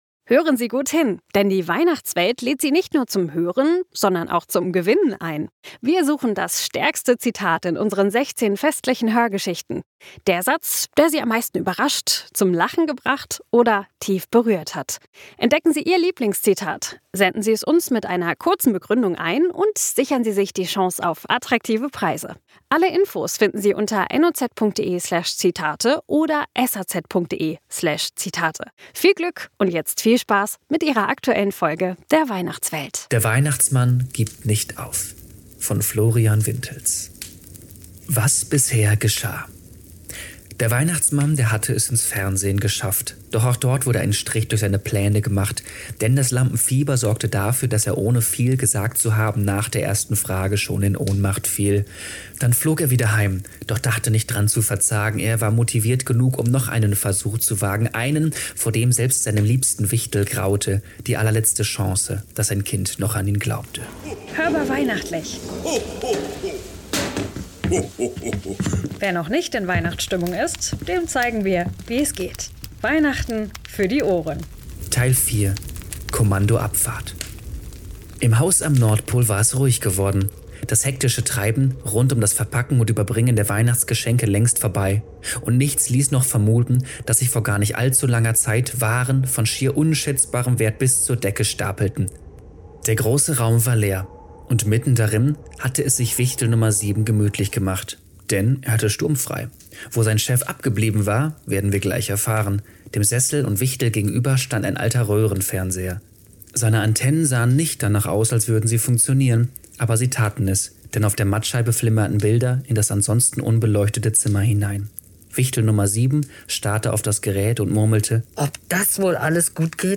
Text/Erzähler